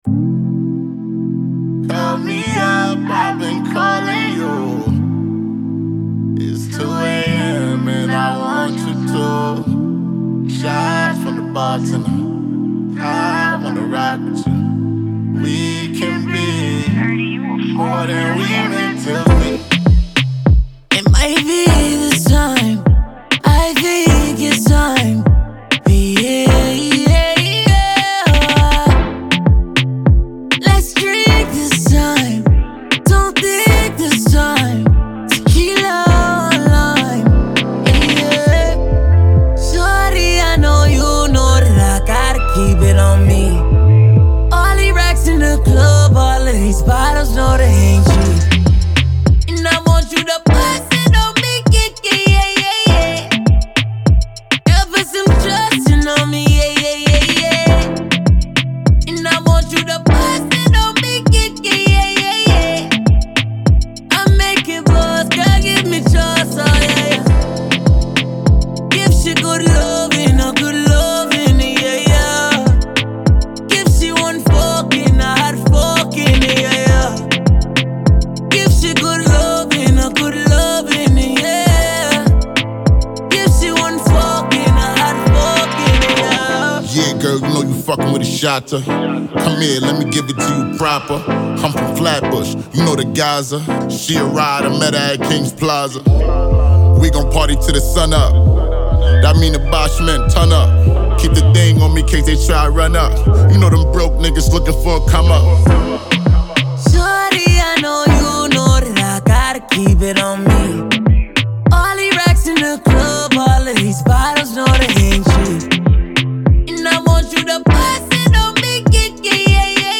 Brooklyn Rapper